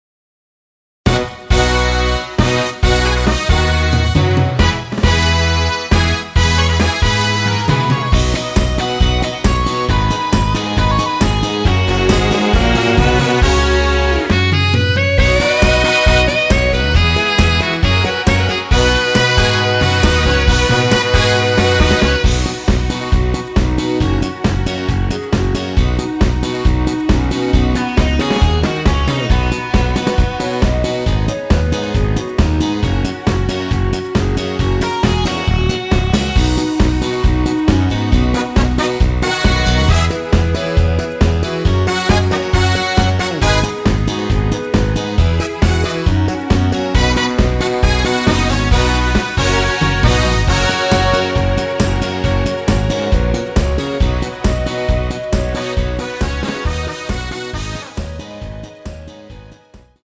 원키에서(+1)올린 멜로디 포함된 MR입니다.
◈ 곡명 옆 (-1)은 반음 내림, (+1)은 반음 올림 입니다.
앞부분30초, 뒷부분30초씩 편집해서 올려 드리고 있습니다.
중간에 음이 끈어지고 다시 나오는 이유는